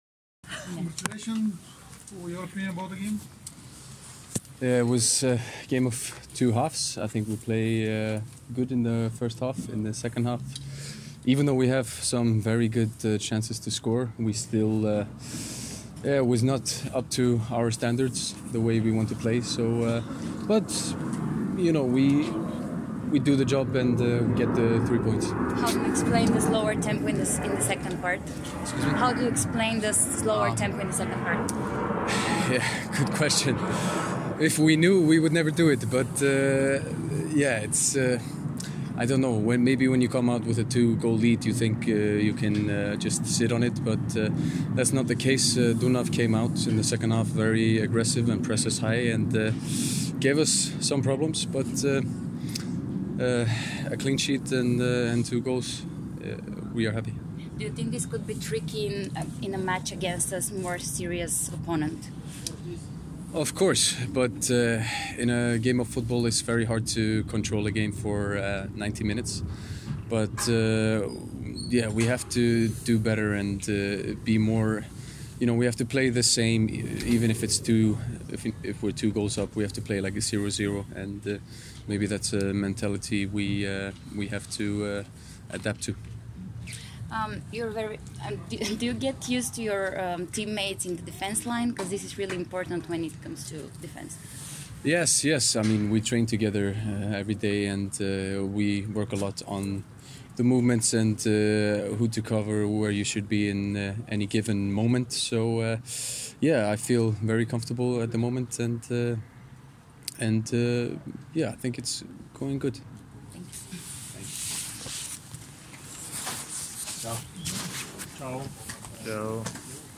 Голмайсторът Холмар Ейолфсон направи изявление пред медиите след победата на Левски с 2:0 срещу Дунав на стадион „Георги Аспарухов“.